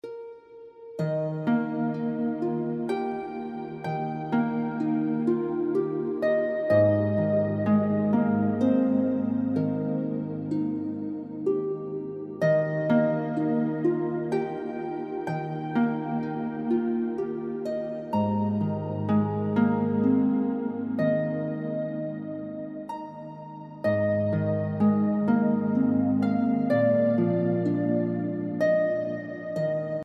Heavenly Harp Music Tag: harp